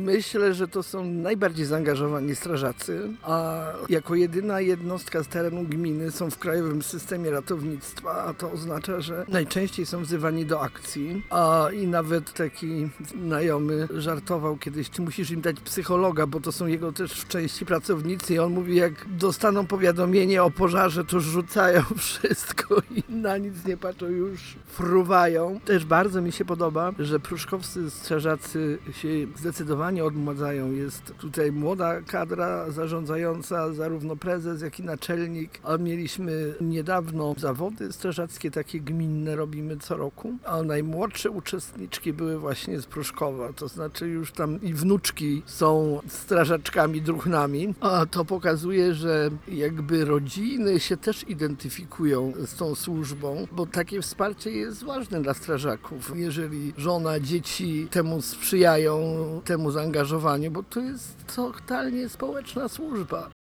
– mówi Róża Malik.
1-roza-malik-burmistrz-proszkowa-140-lecie-instnienia-osp-ochotniczej-strazy-pozarnej.wav